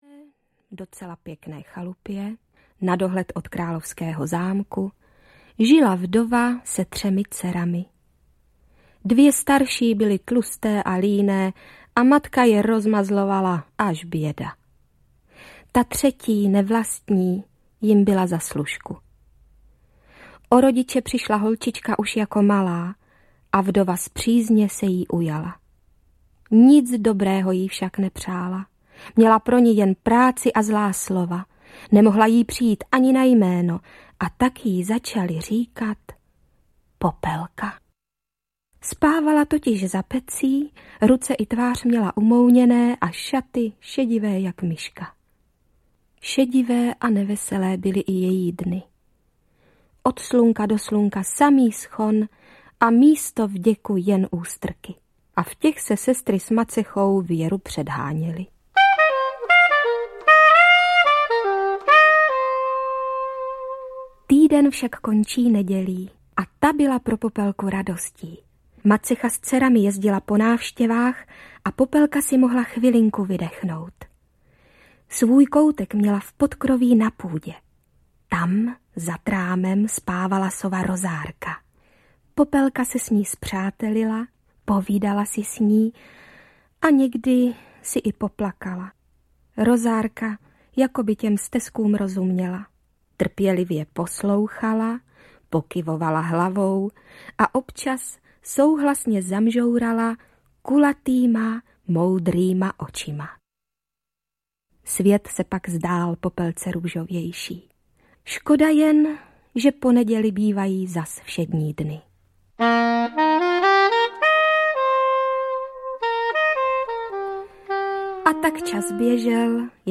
Popelka a další pohádky audiokniha
Ukázka z knihy
Album pohádek pro malé i velké posluchačeNejznámější české pohádkové příběhy vyprávějí Libuše Šafránková, Pavel Trávníček a další. Ústřední dvojice z filmu Tři oříšky pro Popelku se v nahrávacím studiu vrátila k českým pohádkám slavným i díku filmovému zpracování - Tři oříšky pro Popelku, Malá mořská víla a Třetí princ. Album doplňuje i dramatizovaná pohádka O Popelce s Janou Preissovou, Janou Hlaváčovou, Viktorem Preissem a Luďkem Munzarem.